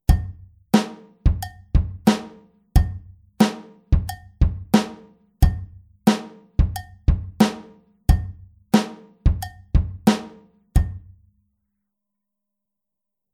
Paradiddle Kapitel 1 → Rechte Hand auf Kopfbecken (bell/head) oder Kuhglocke (cowbell) - Musikschule »allégro«
Bei der 4tel-Variante des Grooves empfiehlt es sich, die rechte Hand nicht mehr auf dem HiHat zu spielen sondern auf dem Kopfbecken (bell/head) oder der Kuhglocke (cowbell). Dadurch bekommt das Ganze wesentlich mehr Punch (Kraft oder Knack):
Groove02-4tel.mp3